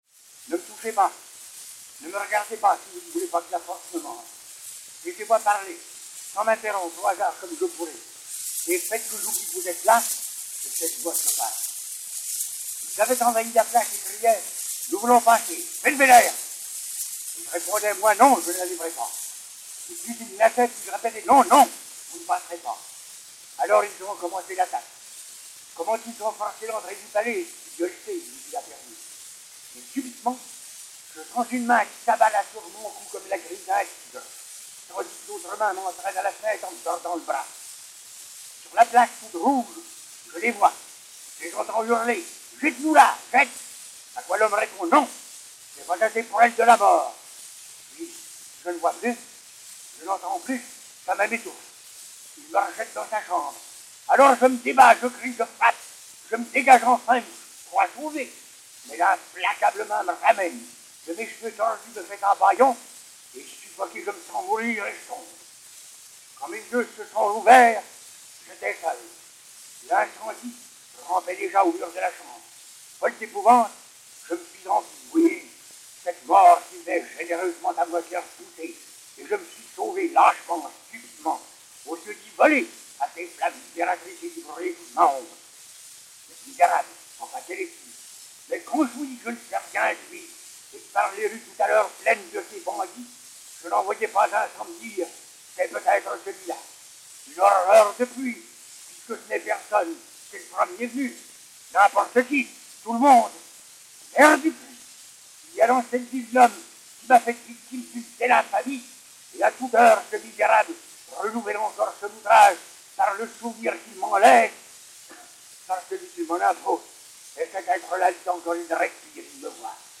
Victorien Sardou a « dit » les trois disques suivants (27 cm. 12fr.50) : fragments de :
Victorien Sardou (Cordelia)